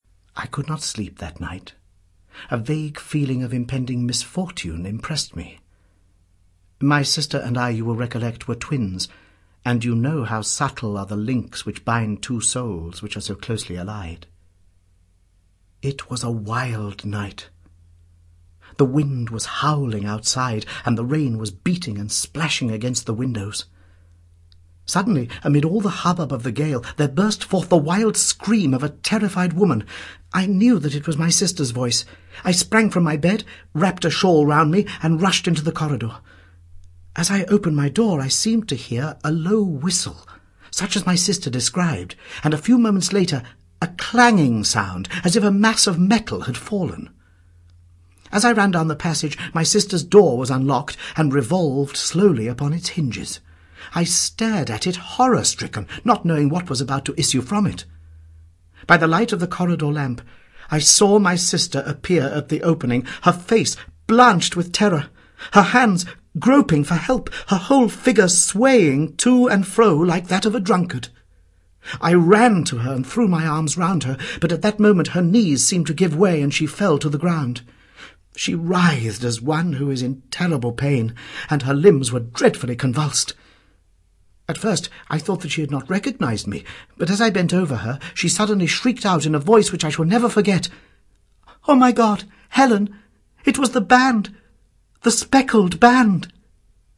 The Adventures of Sherlock Holmes I (EN) audiokniha
Ukázka z knihy